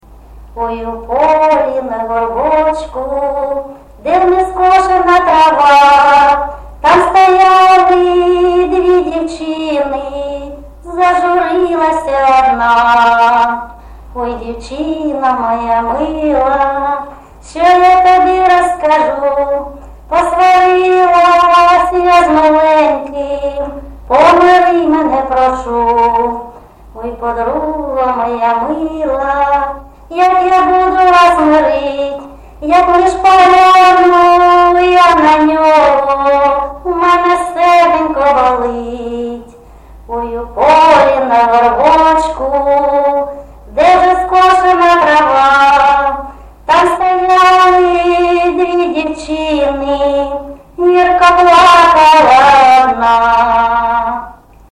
ЖанрПісні з особистого та родинного життя
Місце записус-ще Троїцьке, Сватівський район, Луганська обл., Україна, Слобожанщина